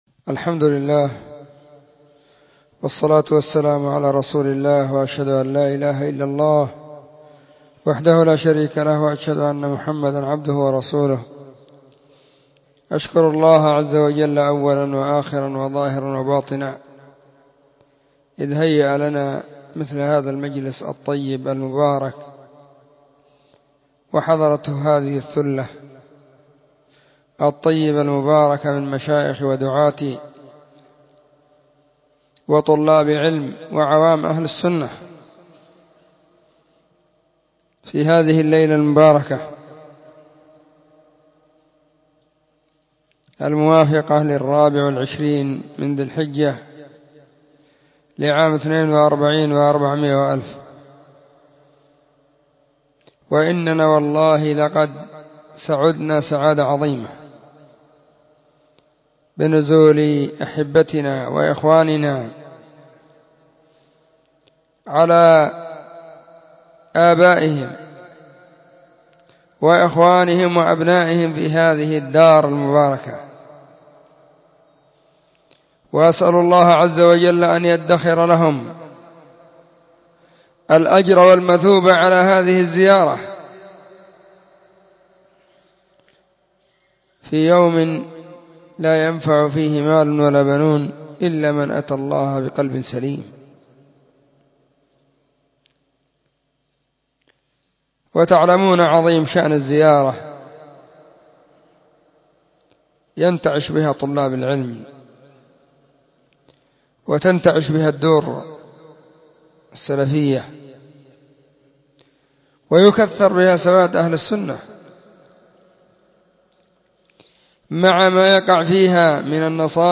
🎙كلمة بعنوان:🏝ترحيب وشكر الضيوف من محافظة تعز🏝